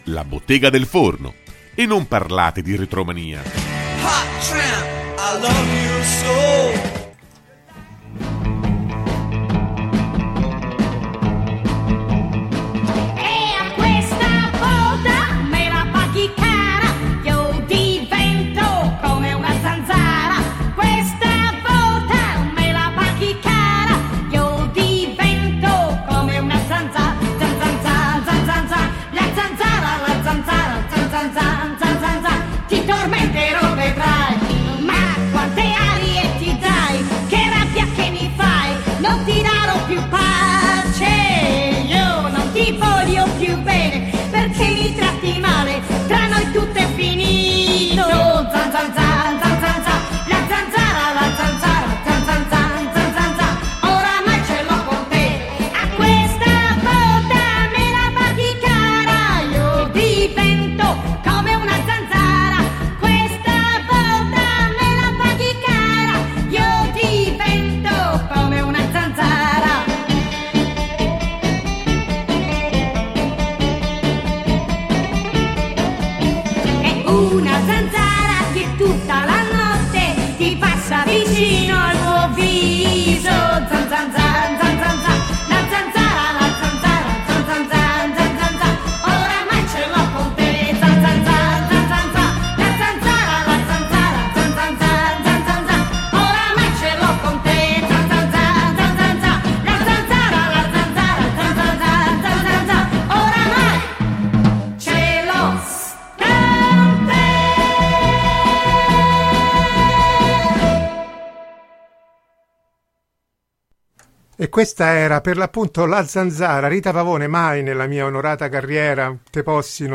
L’intervista è stata ovviamente corredata dall’ascolto di alcuni brani tratti dai film raccolti nel libro.